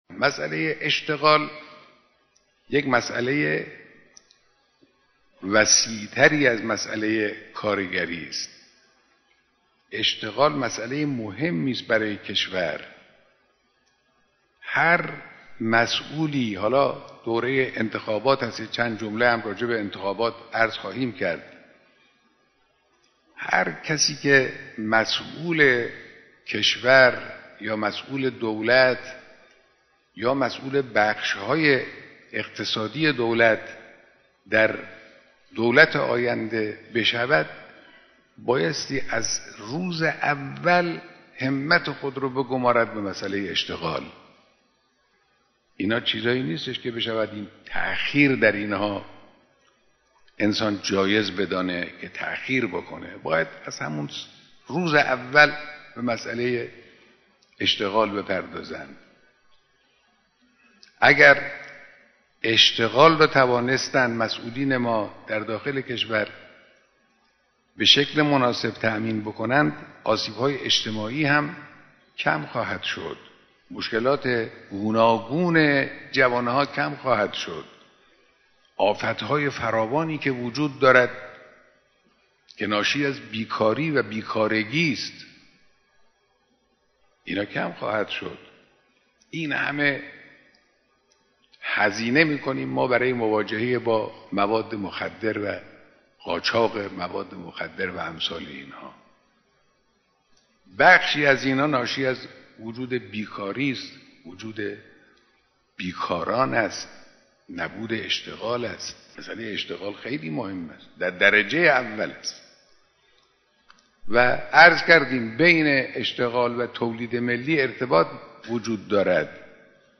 در آستانه‌ روز کار و کارگر، جمعی از کارگران سراسر کشور، صبح امروز (یکشنبه) با حضور در حسینیه‌ امام خمینی (ره) با حضرت آیت‌الله خامنه‌ای رهبر انقلاب اسلامی دیدار کردند.